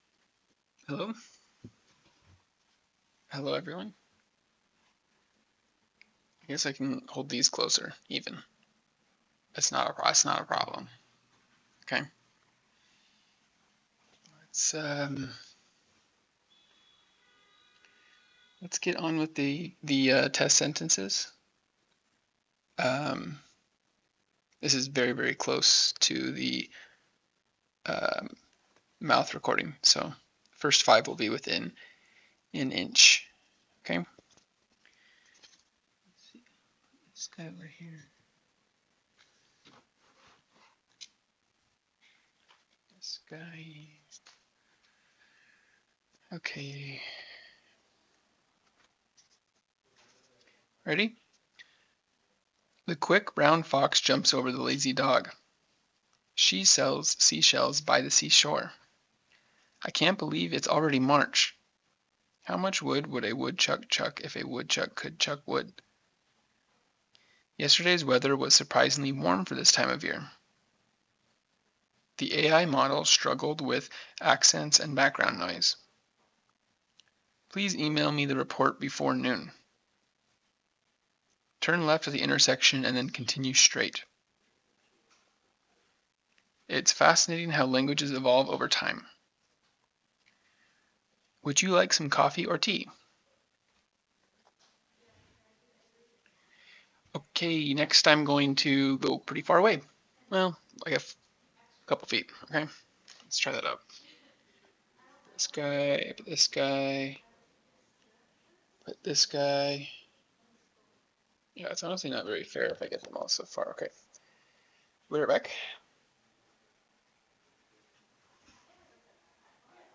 wireless_boya.wav